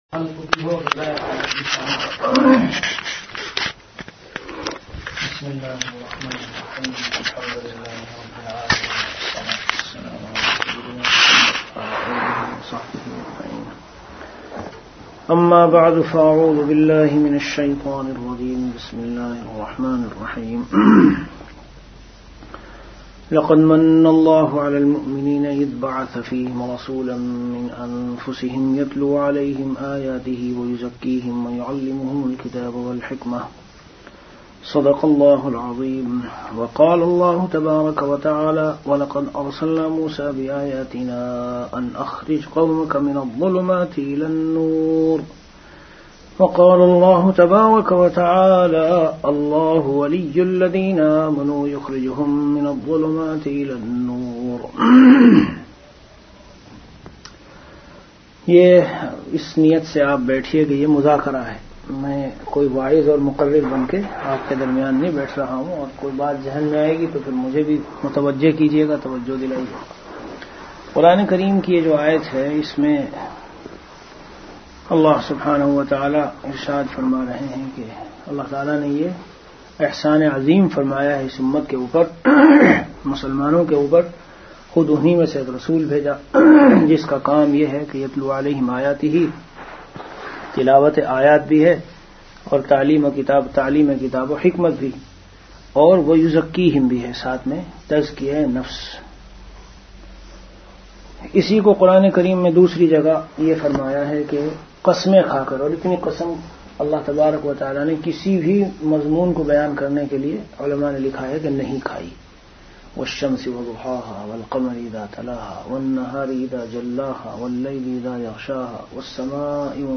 Delivered at Qatar.
Bayanat · Qatar Tazkiya e Nafs Ka Asaan Rasta